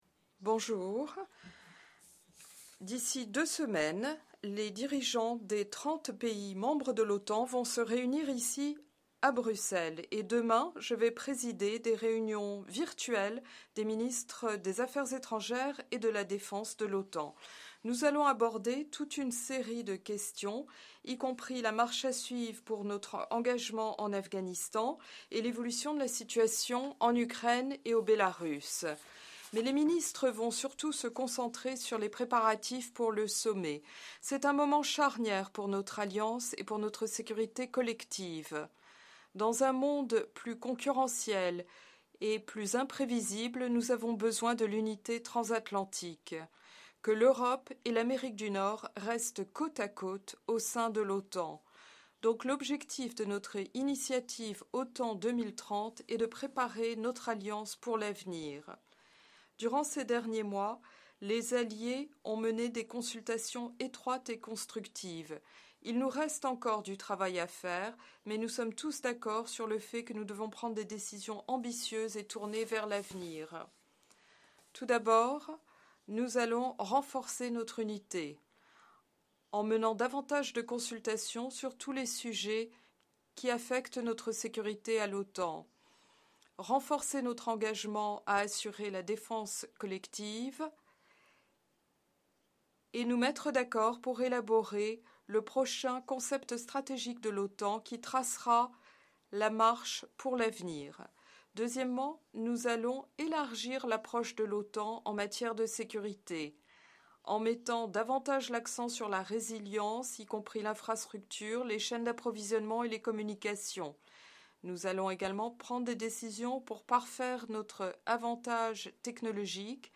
ORIGINAL - Opening remarks by NATO Secretary General Jens Stoltenberg at the meeting of the North Atlantic Council in Foreign Affairs Ministers’ session via video-conferenc